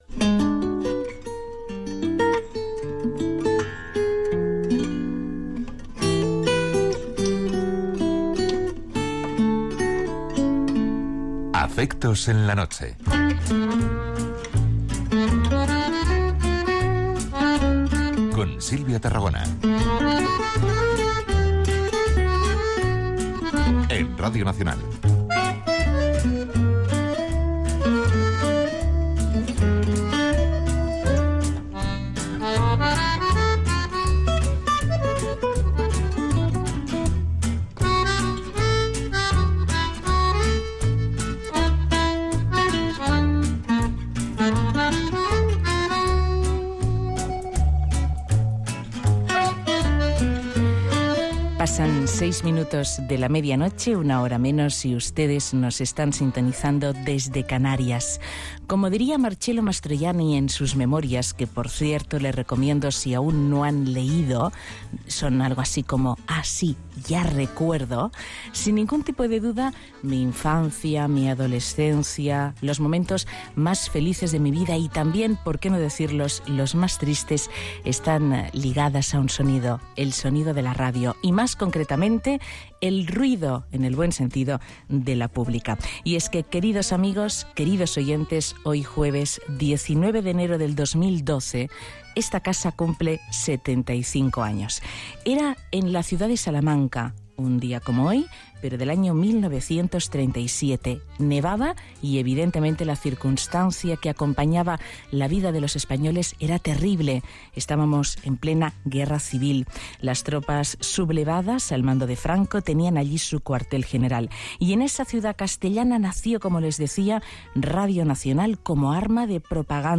75 anys del naixement de Radio Nacional de España a Salamanca. Recreació de la primera emissió.
Gènere radiofònic Entreteniment